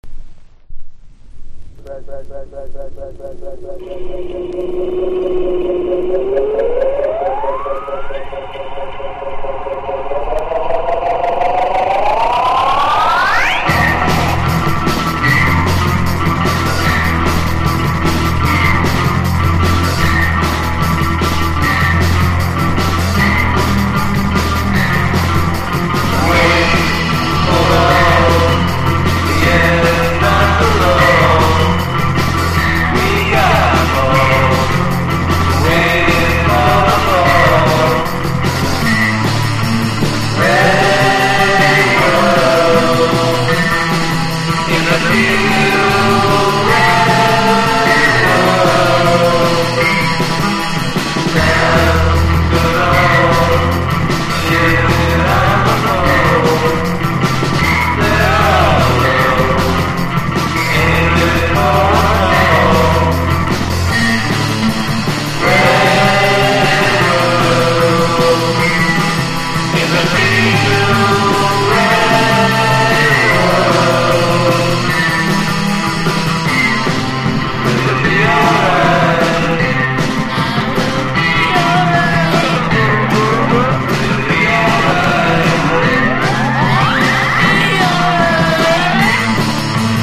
1. 90'S ROCK >
ローファイでポップなサウンドやらせたら間違いないです。